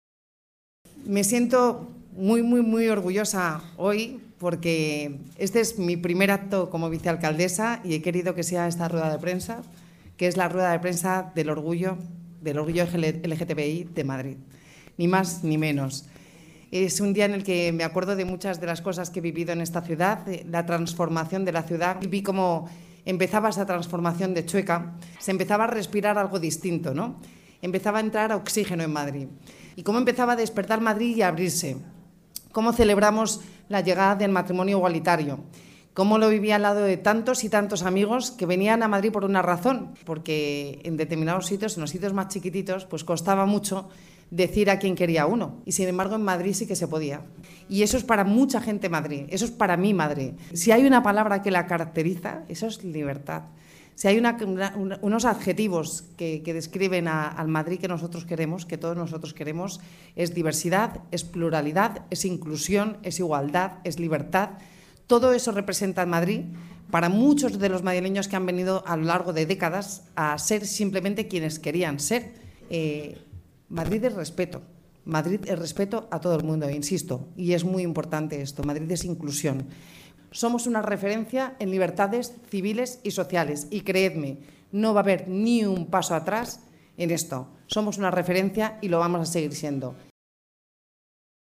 Begoña Villacís en la presentación del Orgullo Madrid MADO 2019